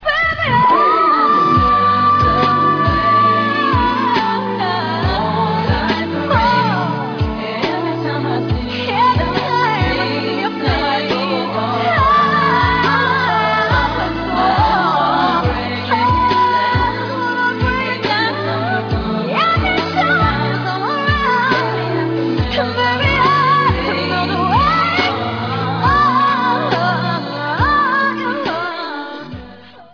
background vocals and keyboards